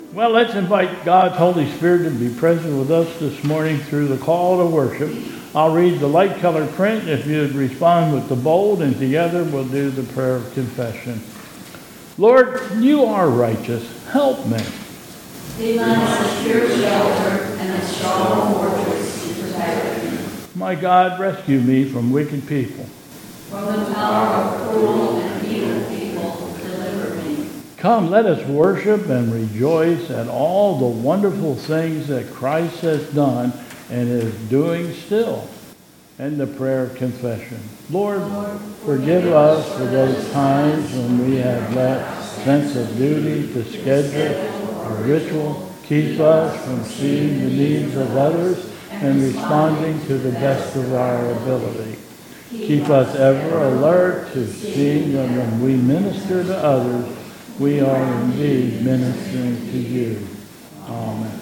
2022 Bethel Covid Time Service
Call to Worship